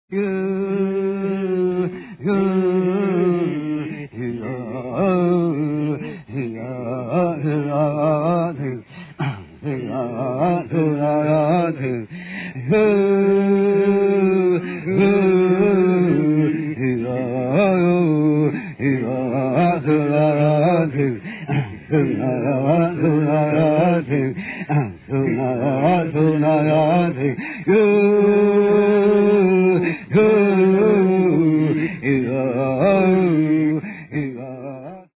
Dance/Chant Ring of Men - 1:35